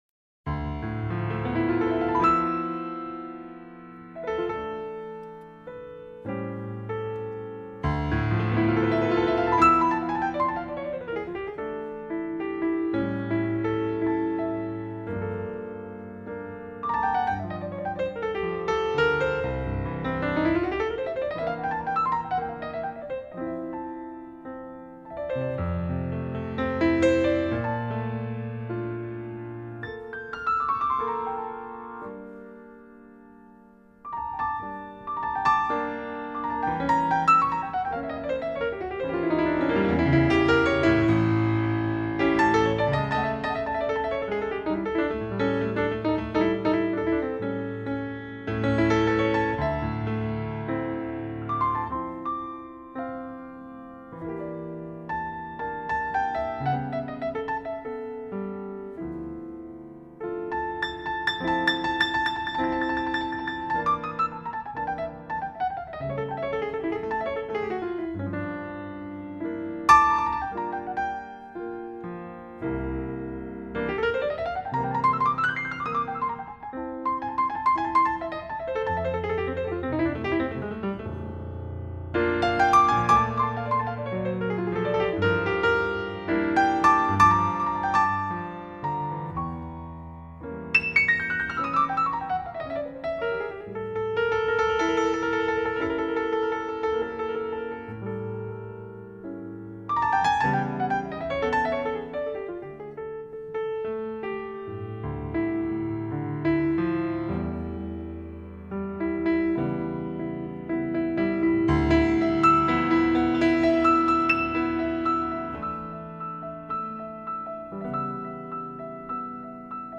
Il jouait de l'orgue et du piano d'une manière extra-ordinaire